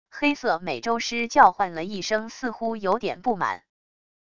黑色美洲狮叫唤了一声 似乎有点不满wav音频